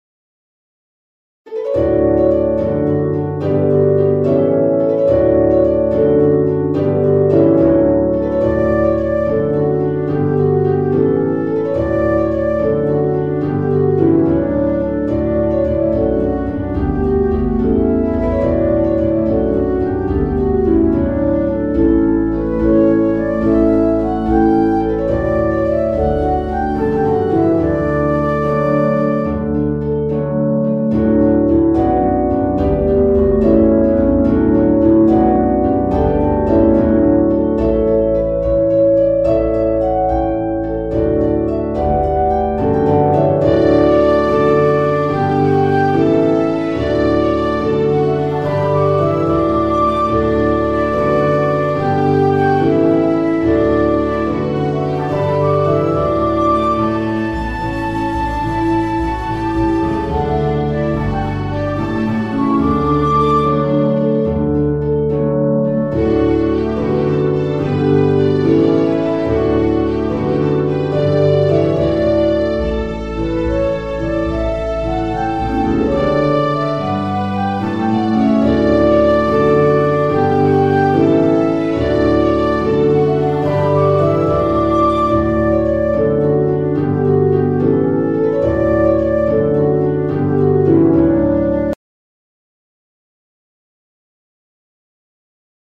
Celtic Style